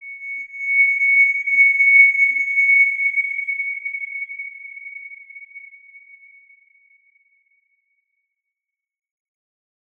X_Windwistle-C#6-pp.wav